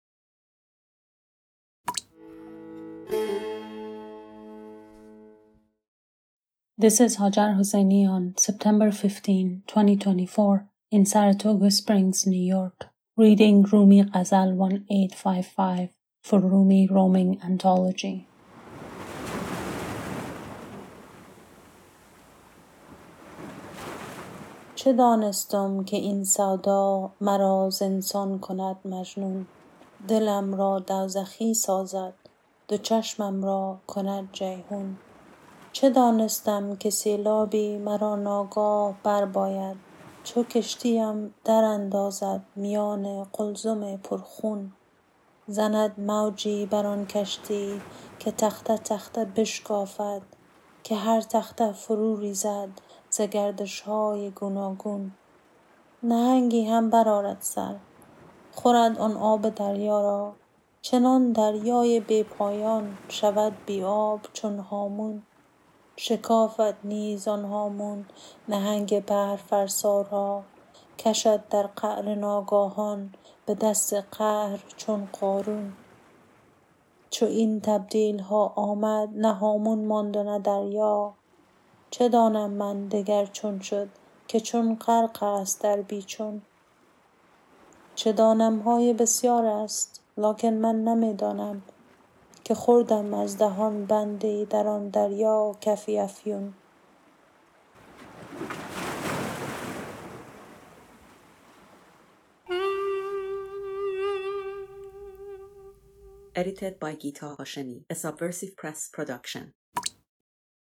Ghazal 1855 by Rumi, read in Persian